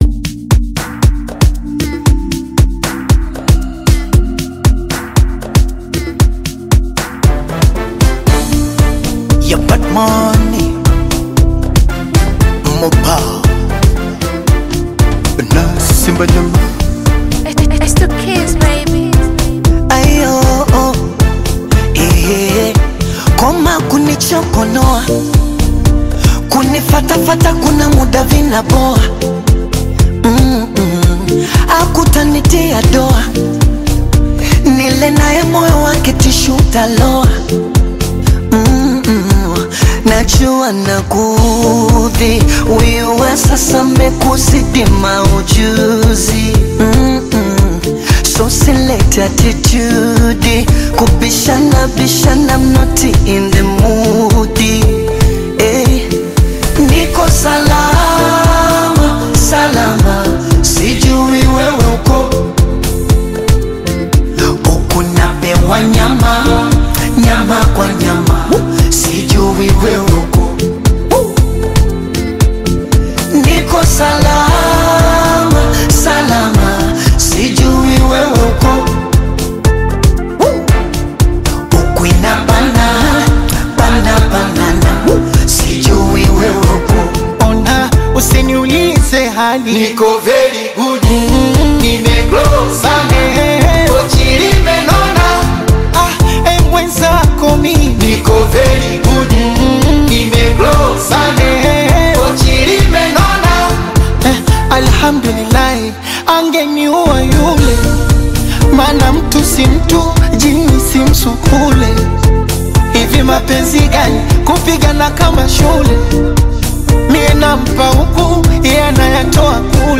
is a soulful Afro-Pop/Bongo Flava single
Genre: Bongo Flava